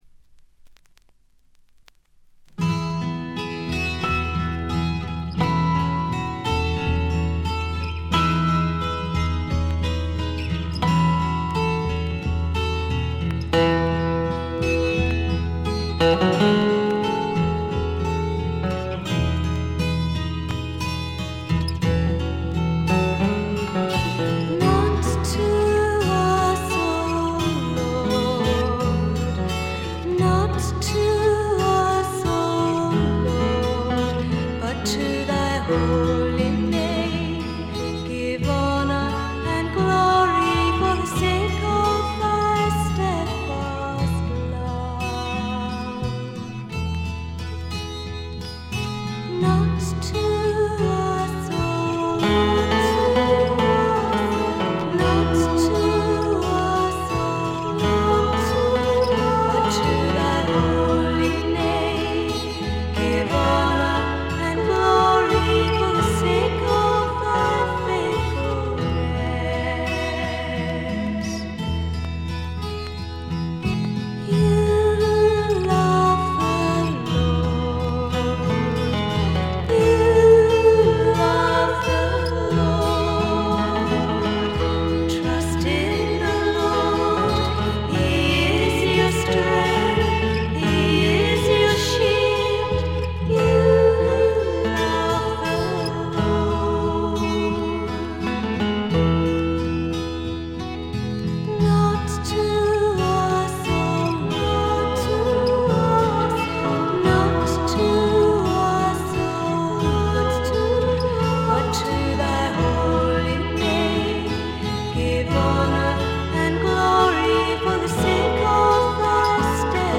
バックグラウンドノイズ、チリプチ多め大きめ。
妖精フィメール入り英国ミスティック・フォーク、ドリーミー・フォークの傑作です。
霧深い深山幽谷から静かに流れてくるような神秘的な歌の数々。
メロトロン入りということでも有名。
それにしても録音の悪さが幸いしてるのか（？）、この神秘感は半端ないです。
試聴曲は現品からの取り込み音源です。